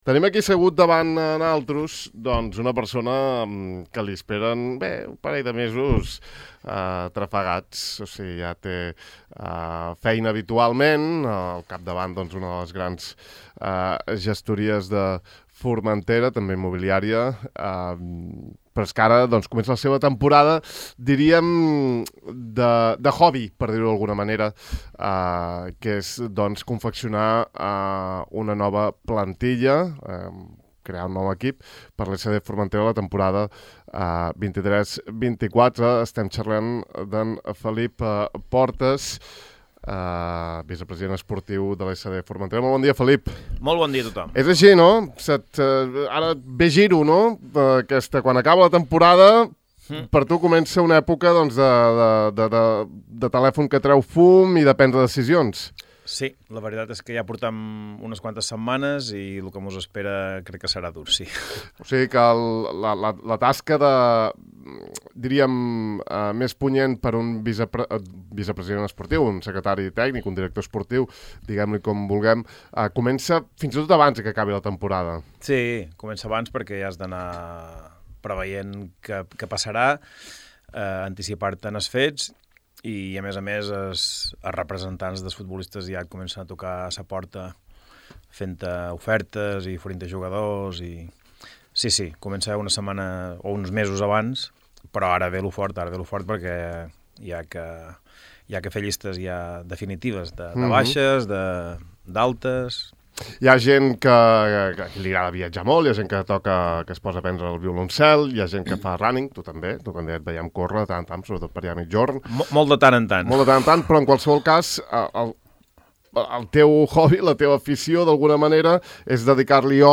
Podeu escoltar l’entrevista sencera clicant sobre aquest reproductor: